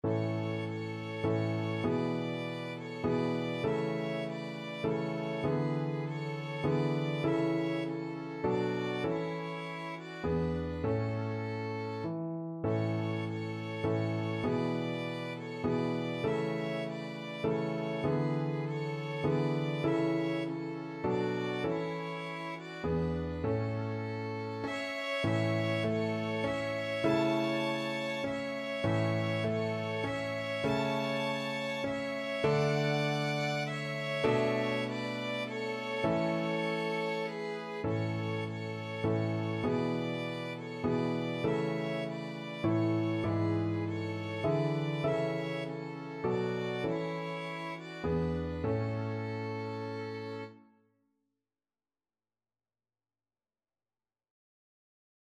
Free Sheet music for Violin Duet
Violin 1Violin 2Piano
A major (Sounding Pitch) (View more A major Music for Violin Duet )
3/4 (View more 3/4 Music)
Traditional (View more Traditional Violin Duet Music)